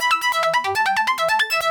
Index of /musicradar/shimmer-and-sparkle-samples/140bpm
SaS_Arp03_140-A.wav